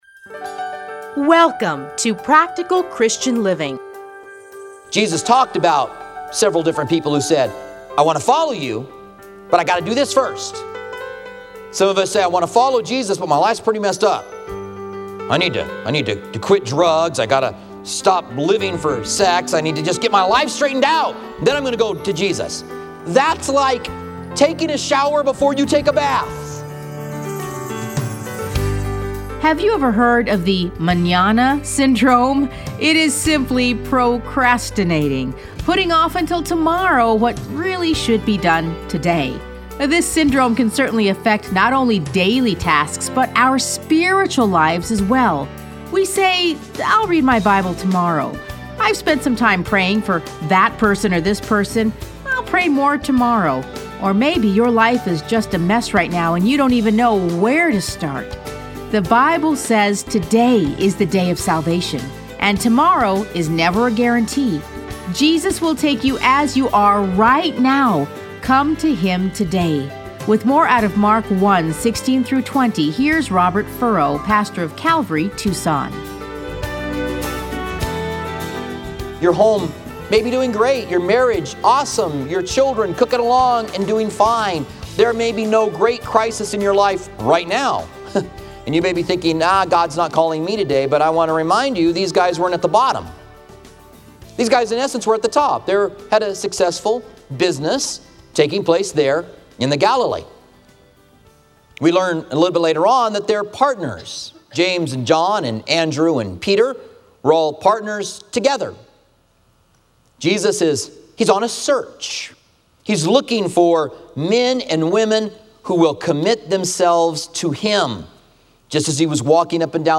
Listen to a teaching from Mark 1:16-20.